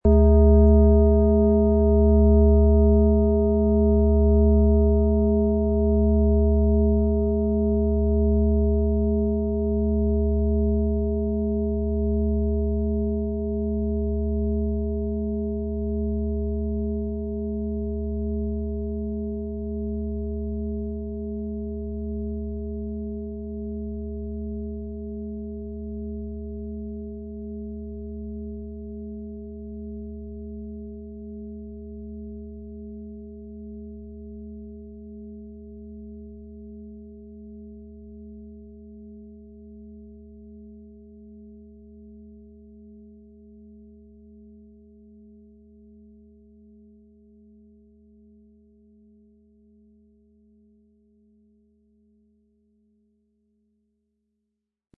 Planetenton 1
XXL Planeten-Fußreflexzonen-Klangschale - Kraftvoller Klang für Motivation und Lebensenergie
Im Sound-Player - Jetzt reinhören hören Sie den Original-Ton dieser Schale. Wir haben versucht den Ton so authentisch wie machbar hörbar zu machen, damit Sie hören können, wie die Klangschale bei Ihnen klingen wird.
Der gratis Klöppel lässt die Schale wohltuend erklingen.